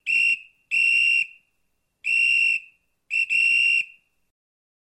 Звуки свистка